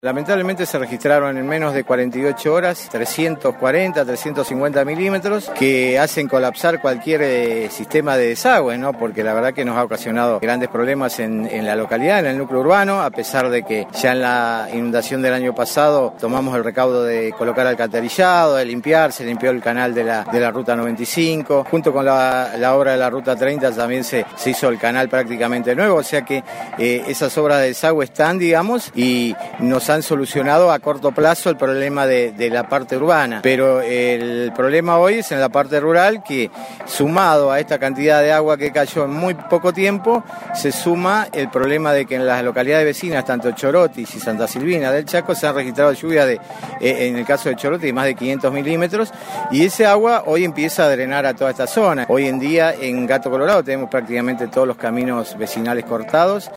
Al respecto, el presidente comunal Fabián Acosta brindó más detalles: